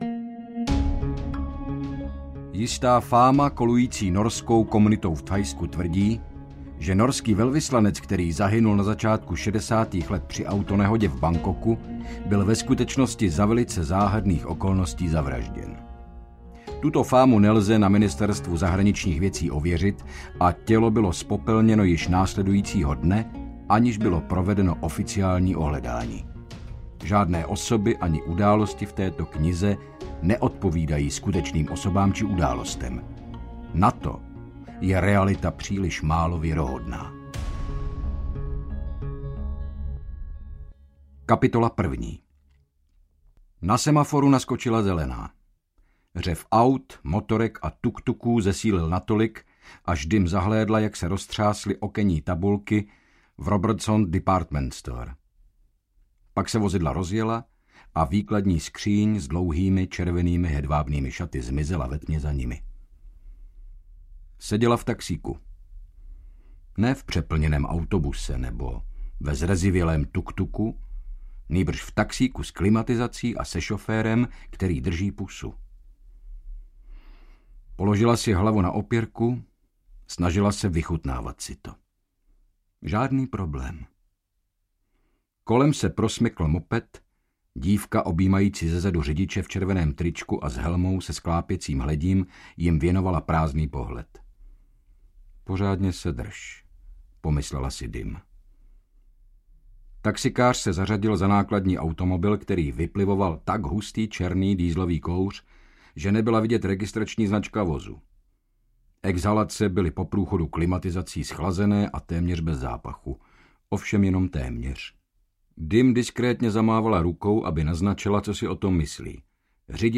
Švábi audiokniha
Ukázka z knihy
• InterpretHynek Čermák